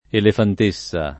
[ elefant %SS a ]